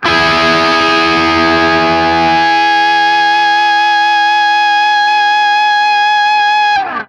TRIAD E   -R.wav